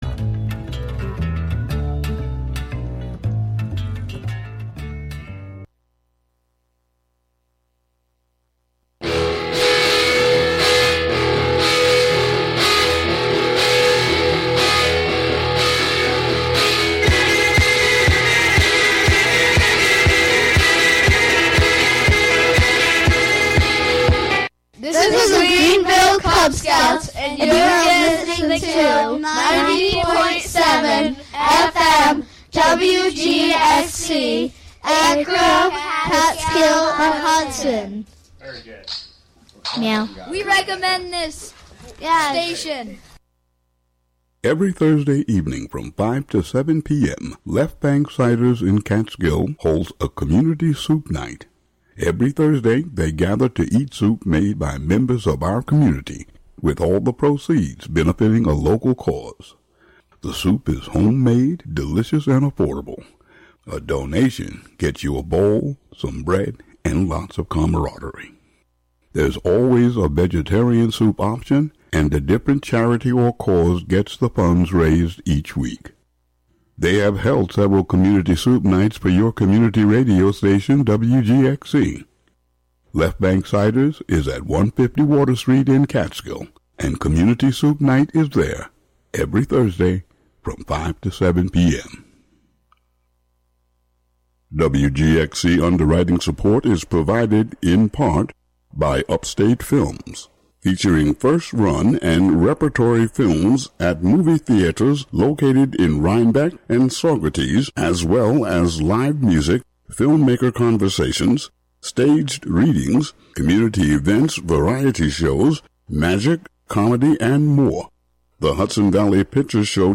Usually the top ten is recent songs, but sometimes there are thematic countdowns, or local music-themed shows.